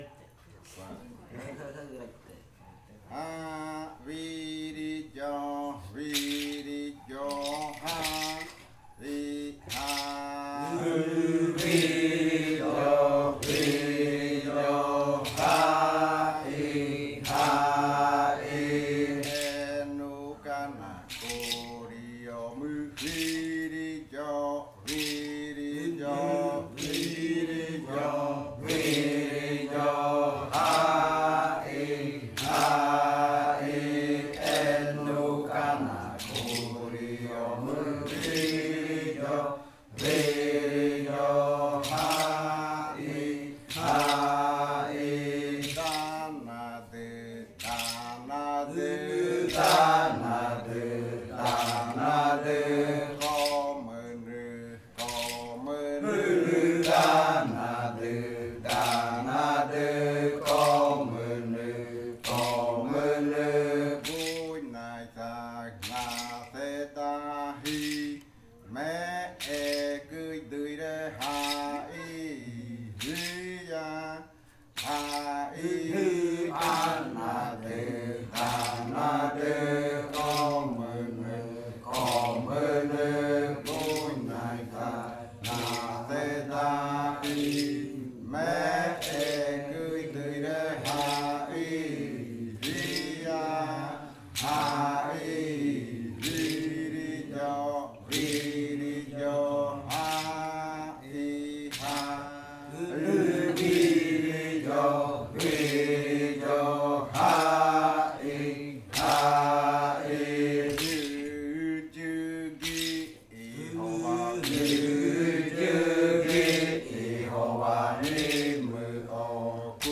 Primer canto de entrada (arrimada) de la variante muruikɨ
Leticia, Amazonas
con el grupo de cantores sentado (en Nokaido).
with the group of singers seated (in Nokaido).